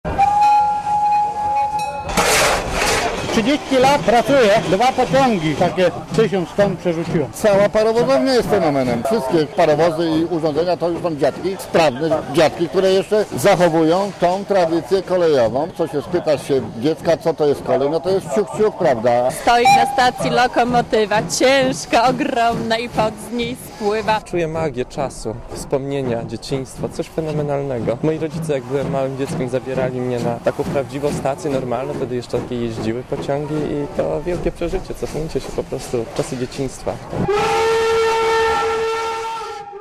(RadioZet) Źródło: (RadioZet) Komentarz audio (310Kb) Atrakcją jubileuszowej imprezy była podróż zabytkowym pociągiem prowadzonym przez lokomotywę z 1921 roku ciągnącą wagony z lat 30. ubiegłego wieku.